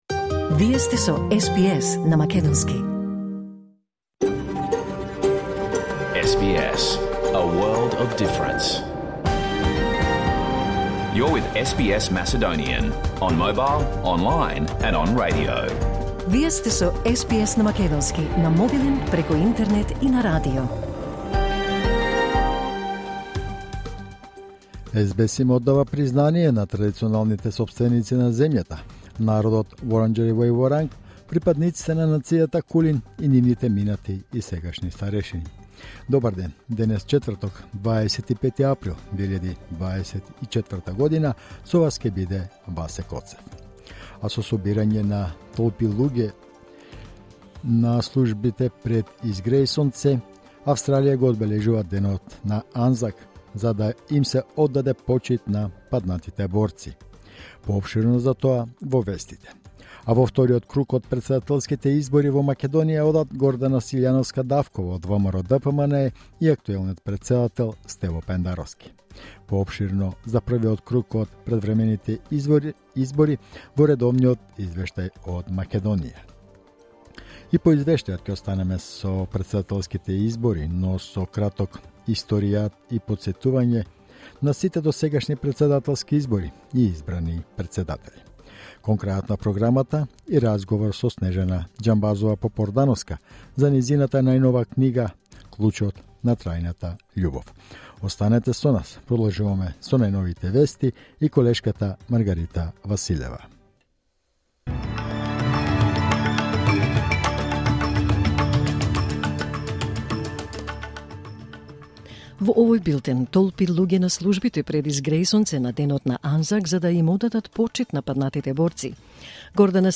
SBS Macedonian Program Live on Air 25 April 2024